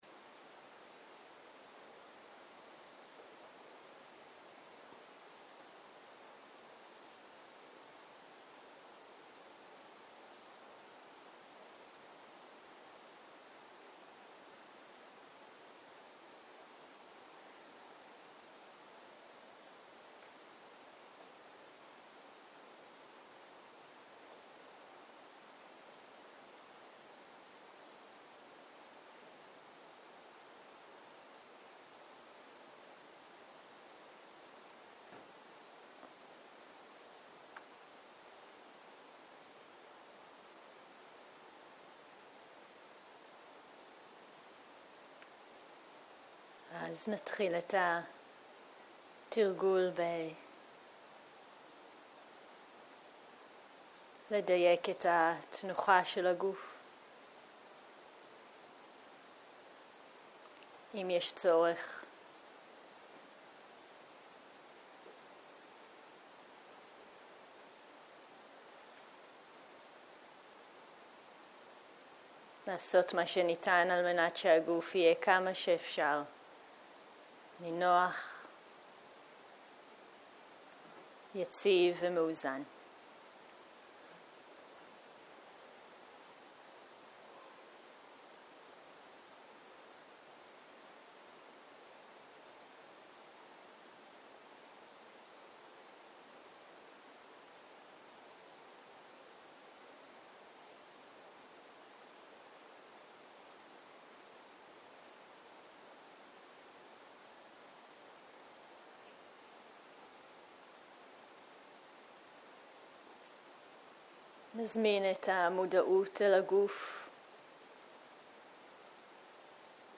צהרים - מדיטציה מונחית - רשמים במודעות
סוג ההקלטה: מדיטציה מונחית